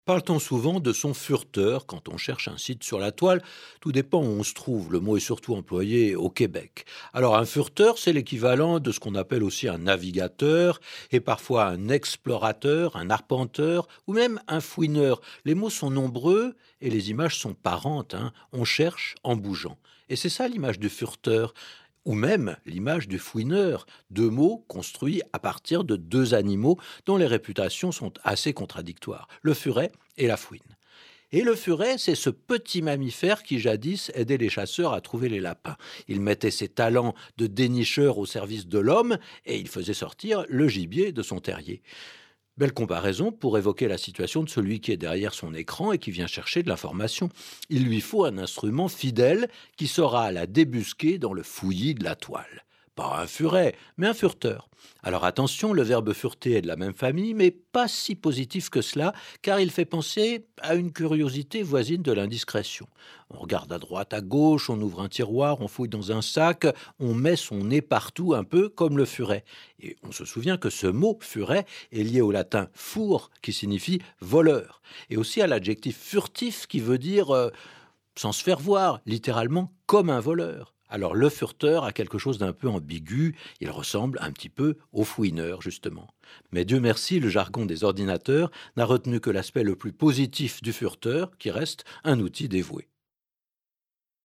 Bande son de la vidéo
dmdm_voix_fureteur.mp3